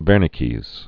(vĕrnĭ-kēz, -kəz)